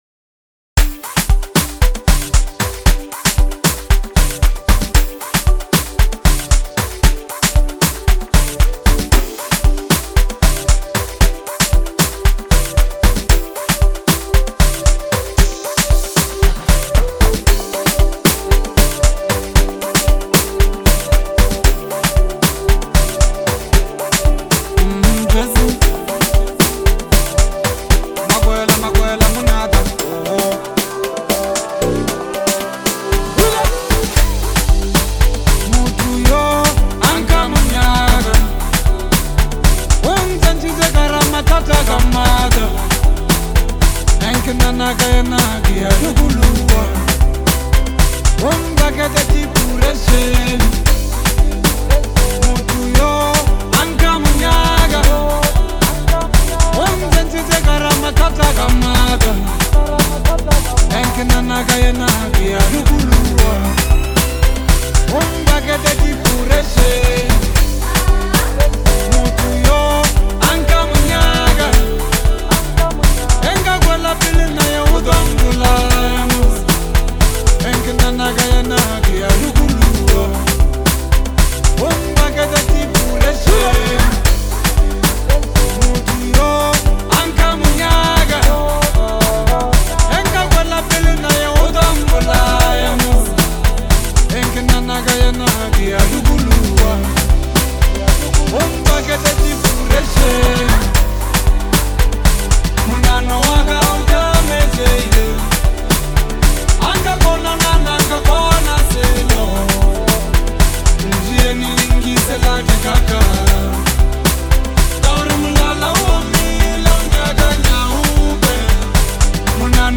melodic, soulful, and catchy.